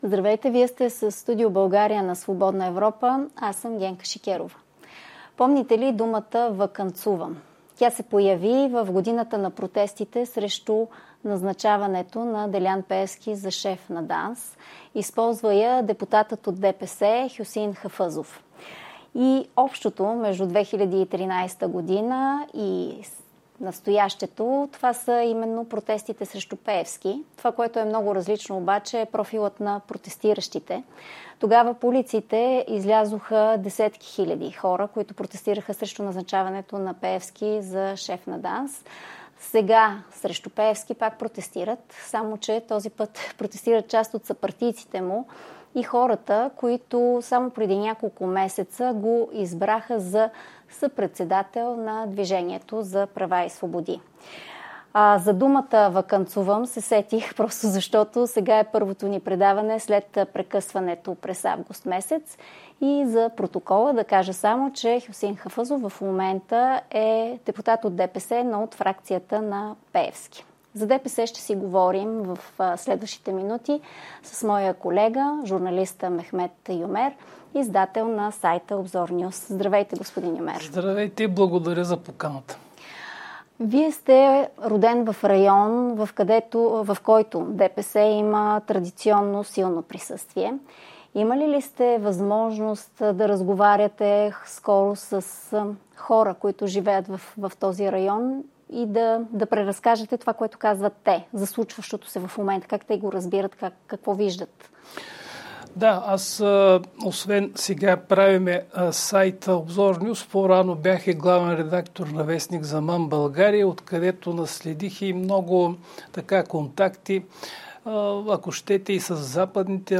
Къде остава почетният председател Ахмед Доган в тази ситуация? А какво ще стане със самата партия и избирателите ѝ? Гост е журналистът